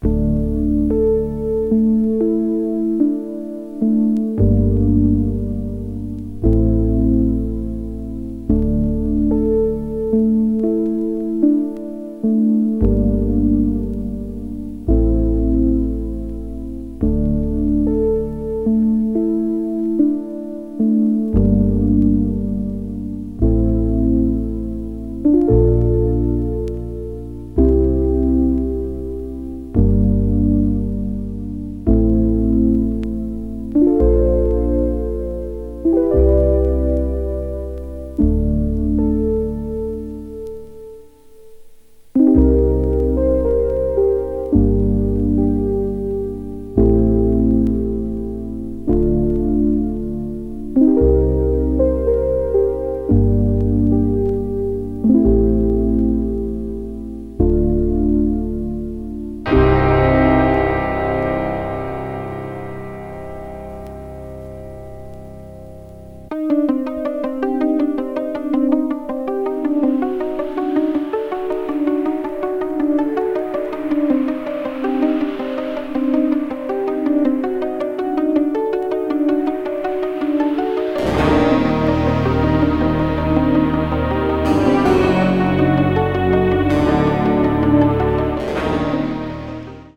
media : EX/EX(some slightly noises.)
ambient   electronic   new age   synthesizer